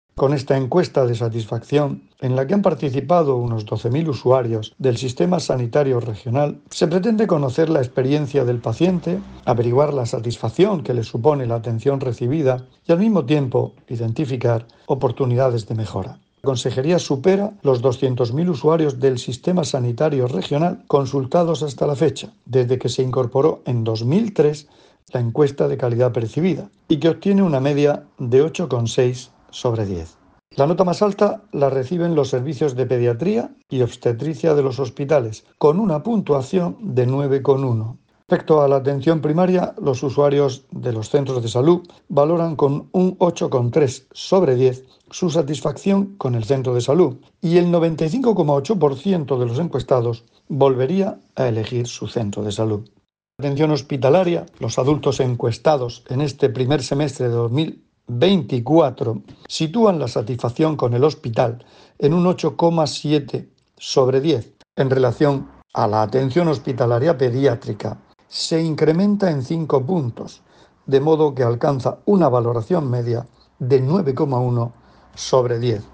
Sonido/ Declaraciones del consejero de Salud sobre los resultados de la encuesta de calidad del SMS.